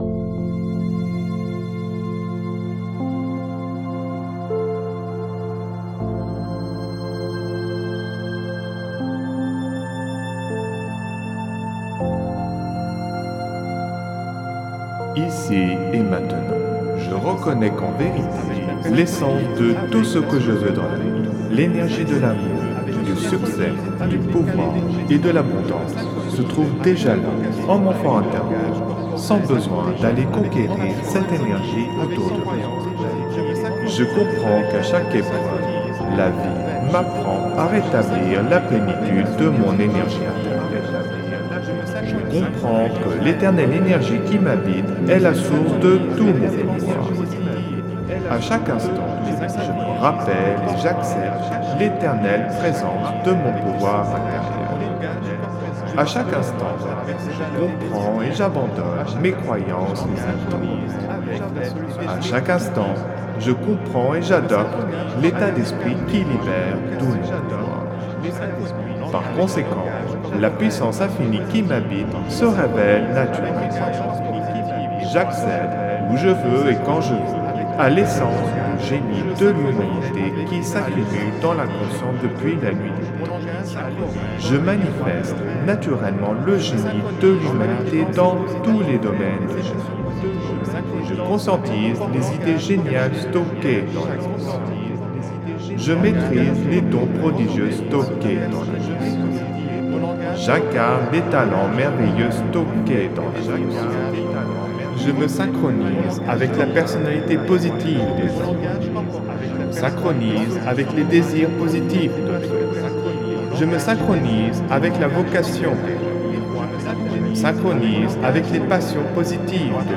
Alliage ingénieux de sons et fréquences curatives, très bénéfiques pour le cerveau.
Pures ondes gamma intenses 64,61 Hz de qualité supérieure. Puissant effet 3D subliminal écho-guidé.